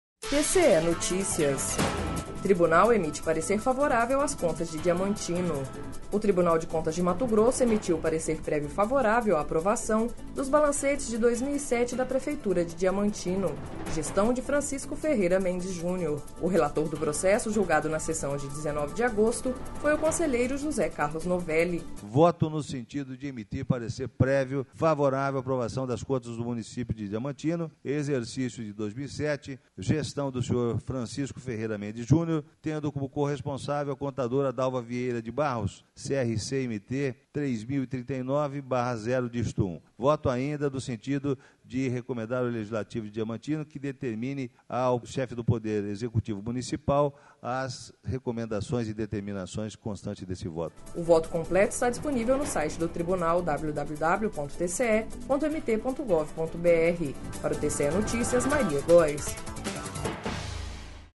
Sonora: José Carlos Novelli - conselheiro do TCE-MT